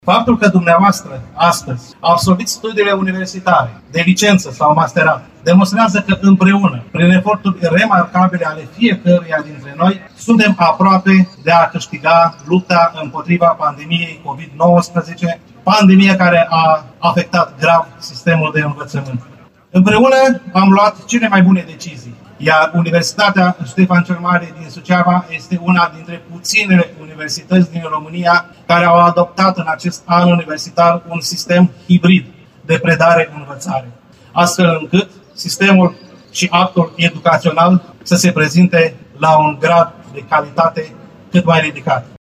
Ieri s-a desfășurat, pe esplanada centrală din Suceava, festivitatea de absolvire a studenților de la Universitatea Ștefan cel Mare.
După marșul absolvenților pe străzile orașului, au urmat discursurile conducerii USV și ale autorităților locale și județene.
Rectorul VALENTIN POPA a felicitat absolvenții și cadrele didactice, accentuând că acțiunile comune au contribuit la depășirea perioadei dificile generate de pandemie.